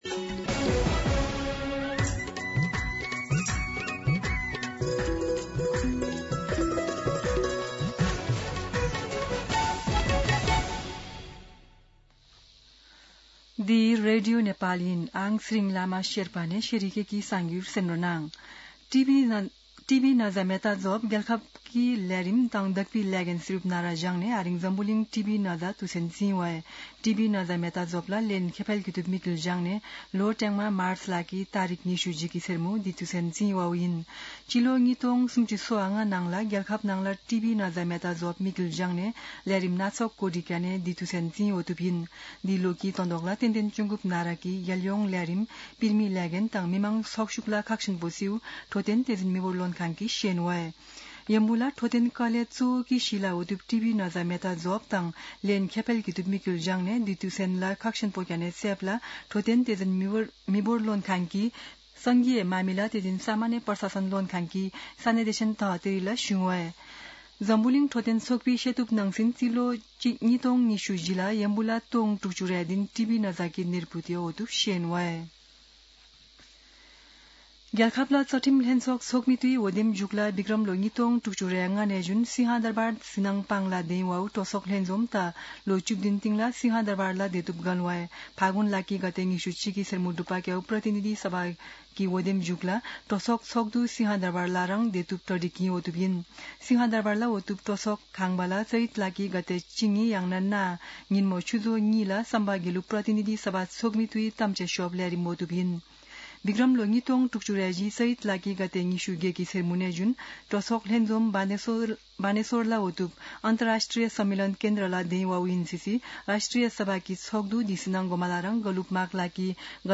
शेर्पा भाषाको समाचार : १० चैत , २०८२
Sherpa-News-10.mp3